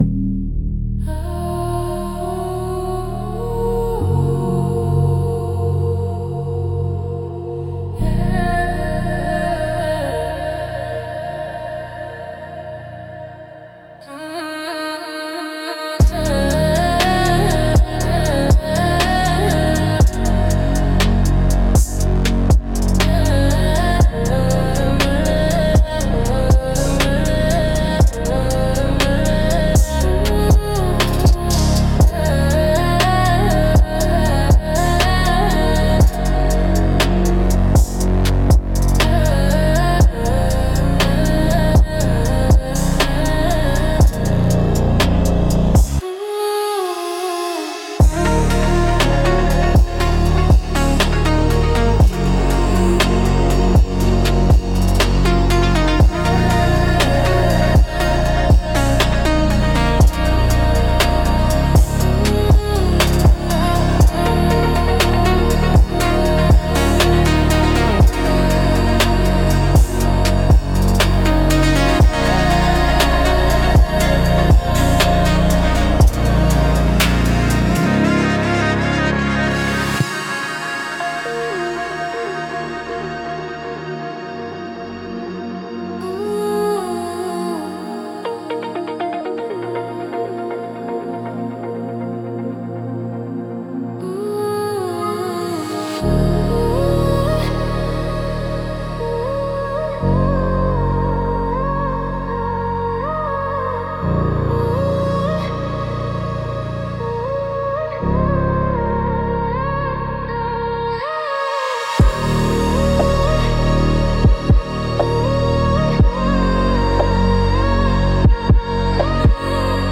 Instrumental - Subterranean 3.44